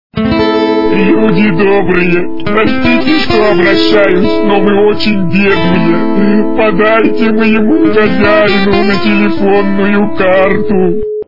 » Звуки » Смешные » Люди, добрые! - Подайте моему хозяину на телефонную карту!
При прослушивании Люди, добрые! - Подайте моему хозяину на телефонную карту! качество понижено и присутствуют гудки.